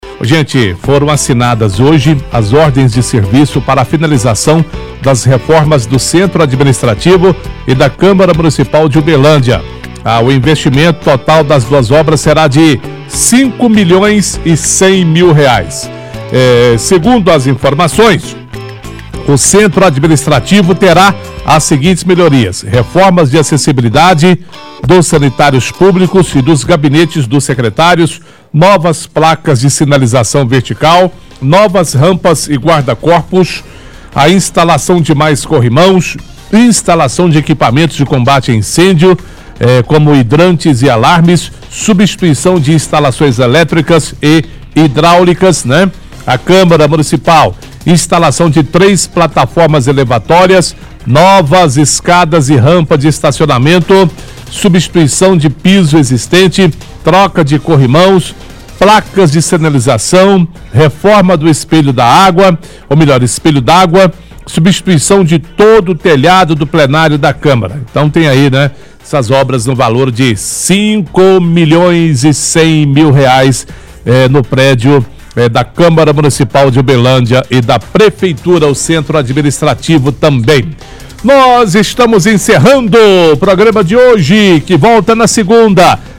lê a reportagem do G1 sobre assinatura de ordem de serviço para reformas da prefeitura e câmara.